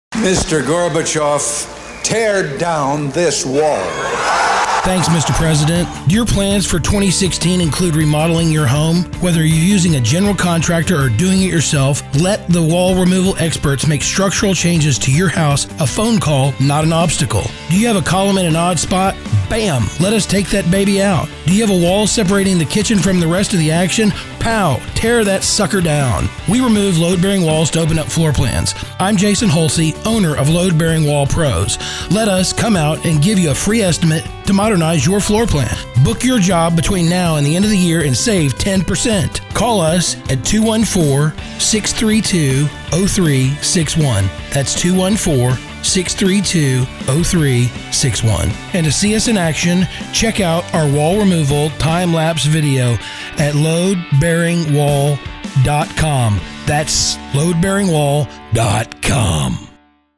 This is our latest ad as heard on the ticket. Book your load bearing wall removal project before the end of the year and slash 10% off of your price too!!